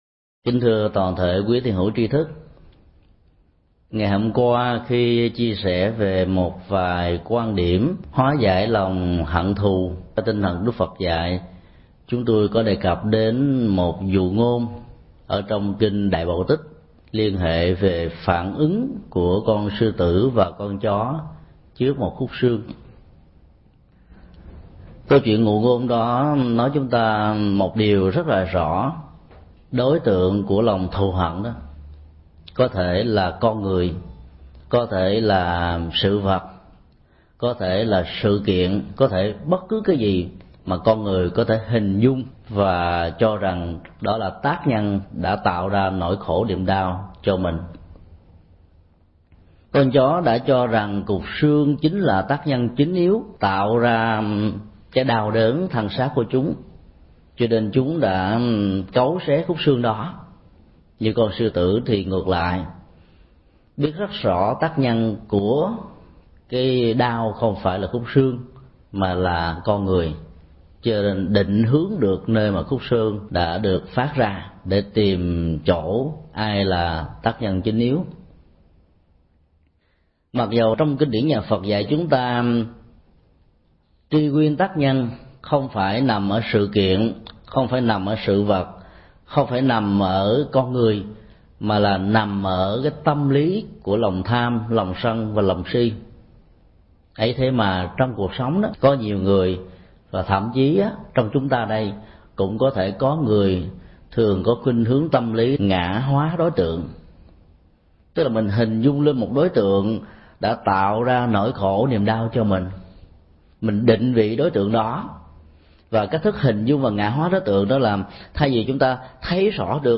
Ngã hóa hận thù – Mp3 Thầy Thích Nhật Từ Thuyết Giảng
Nghe mp3 Pháp Thoại Ngã hóa hận thù – Thầy Thích Nhật Từ, năm 2005